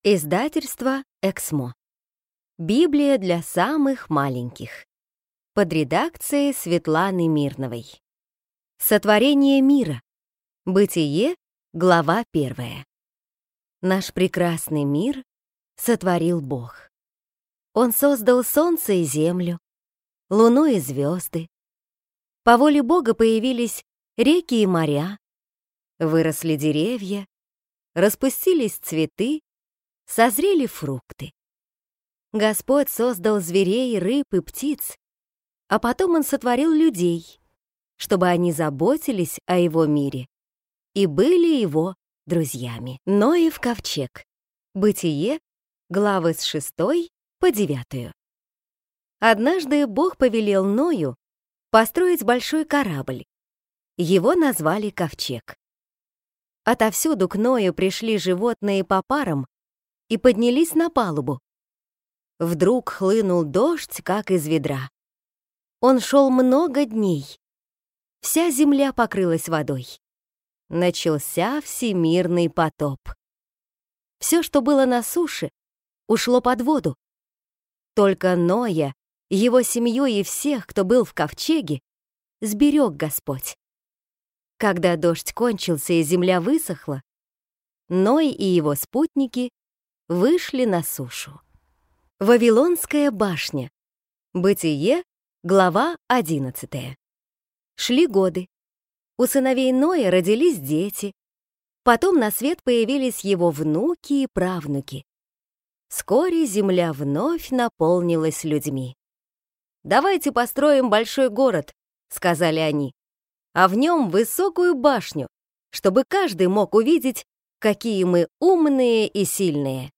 Аудиокнига Библия для самых маленьких | Библиотека аудиокниг